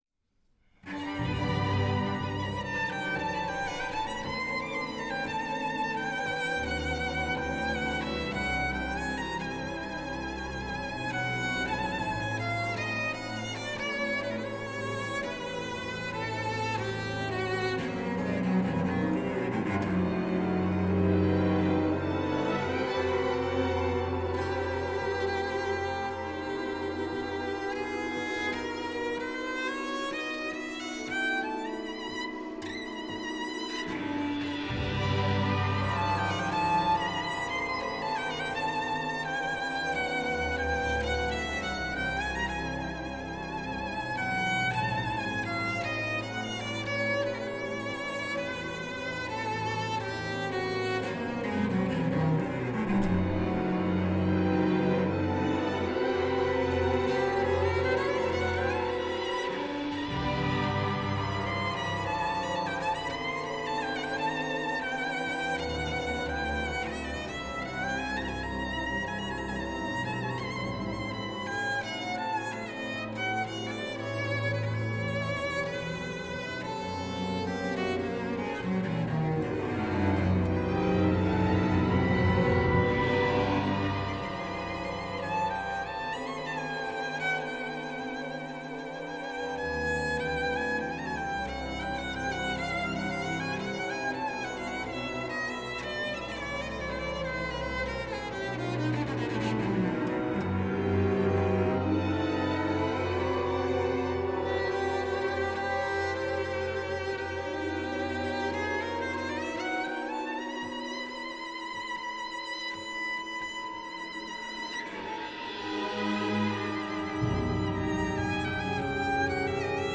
Neumann SM69 Neumann M150 Schoeps MK
Millennia HV-3d & Avid 002 Avalon 747
Orchestra
The Wharton Center, Great Hall . Cellist